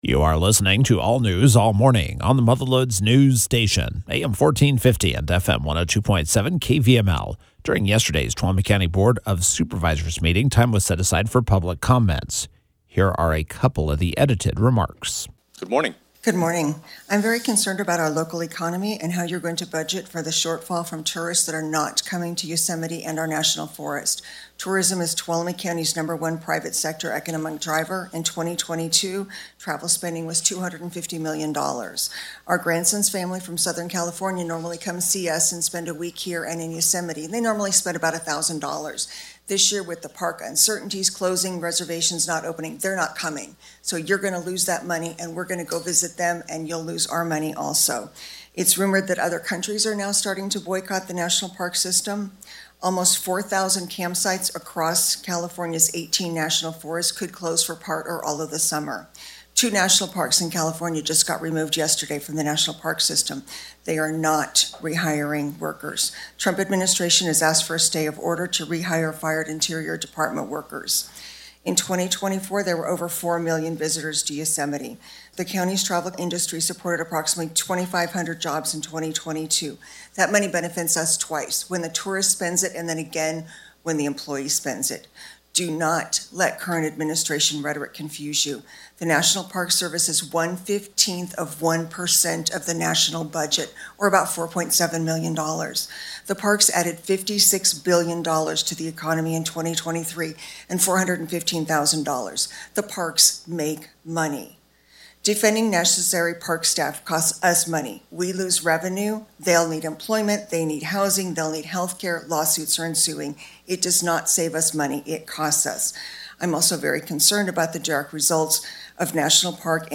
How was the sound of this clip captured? During Tuesday’s Tuolumne County Board of Supervisors meeting, time was set aside for public comments.